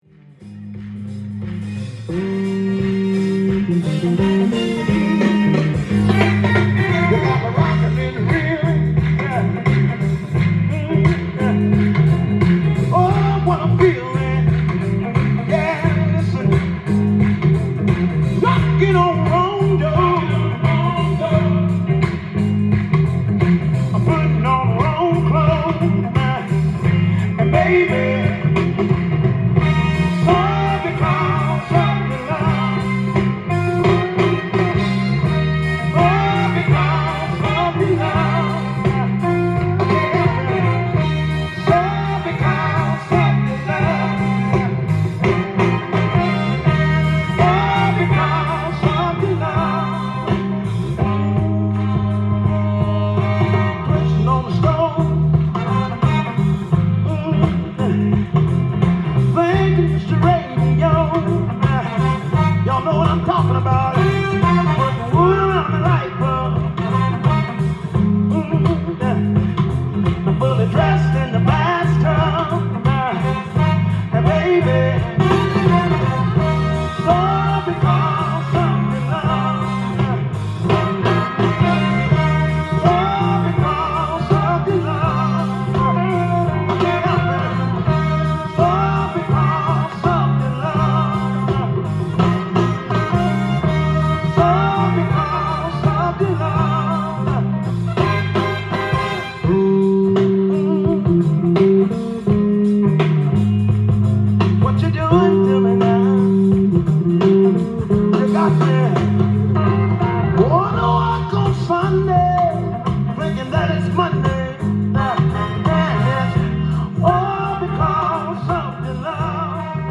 ジャンル：SOUL
店頭で録音した音源の為、多少の外部音や音質の悪さはございますが、サンプルとしてご視聴ください。